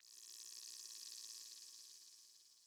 フリー効果音：ジリジリ
電子回路のようなジリジリした環境音です！デジタル空間系の効果音にぴったり！
tingling.mp3